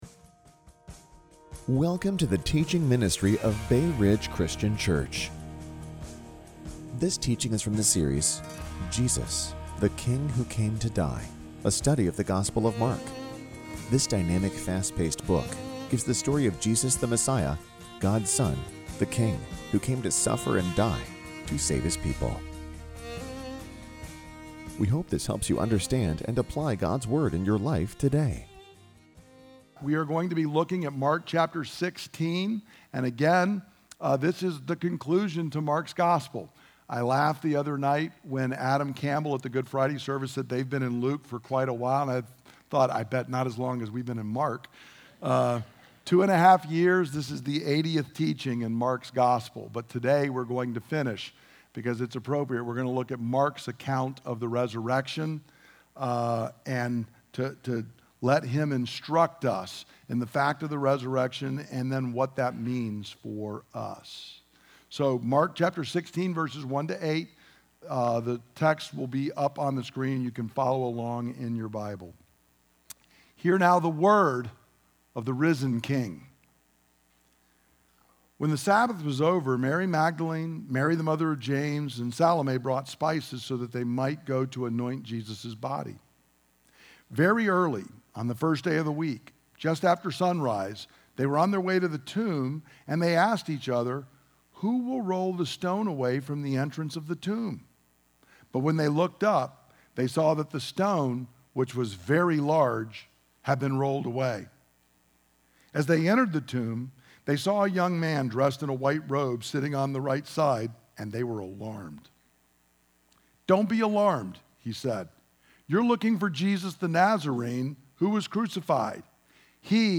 Listen to the teaching – Join us on Facebook or Youtube Live on Sunday @ 10:00 am